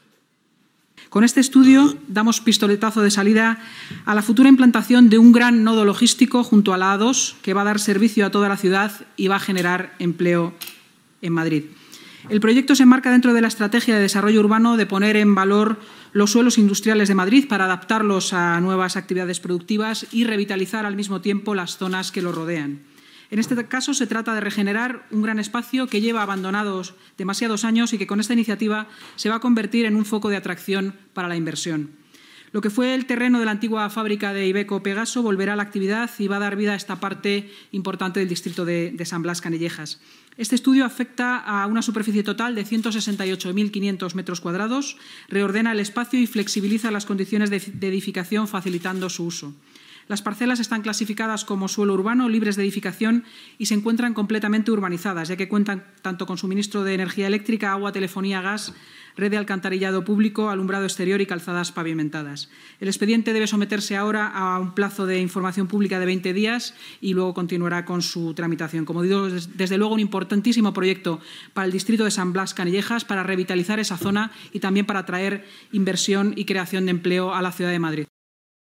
Con este estudio, el Área de Desarrollo Urbano, que dirige Mariano Fuentes, da el pistoletazo de salida para la futura implantación de un gran nodo logístico junto a la A-2 que dará servicio a toda la ciudad y generará empleo, según ha explicado la portavoz municipal, Inmaculada Sanz.